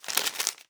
Receipt Handled 03.wav